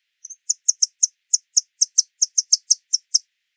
La presencia de estos sonidos puede indicar en algunas especies animales dominancia, apareamiento o cortejo; llamados de alerta al peligro; reconocimiento entre madres y crías etc. Este repositorio digital contiene grabaciones de fauna silvestre residente en la península de Baja California, resultado del proyecto de investigación en el área natural protegida Sierra de la Laguna.
Auriparus_flaviceps_call.mp3